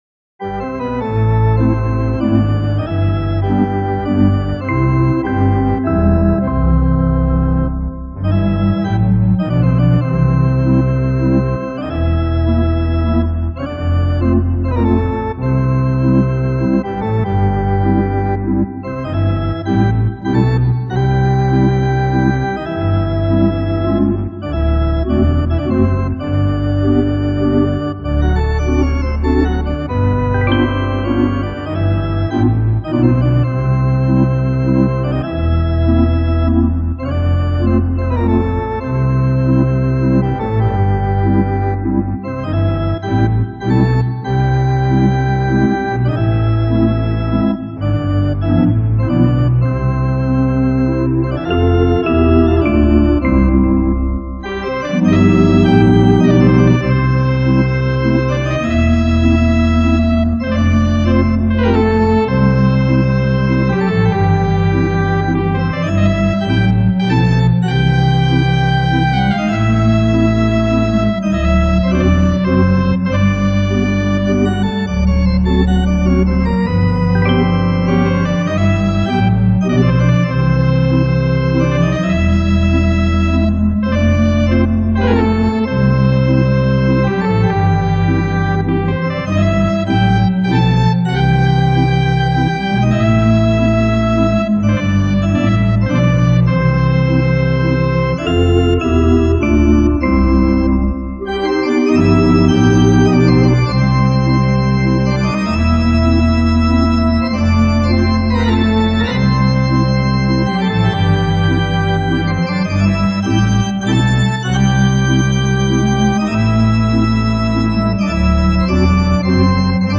The Mighty MidiTzer at Walnut Hill
These were then mixed down to stereo and edited in Sound Forge to get the proper EQ and reverb in the finished product.